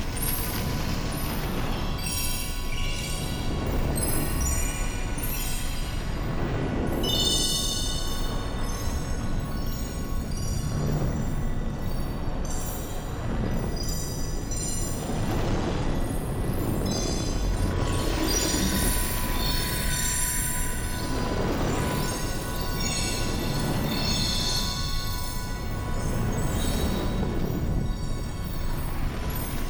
faeriefireloop.ogg